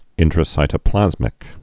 (ĭntrə-sītə-plăzmĭk)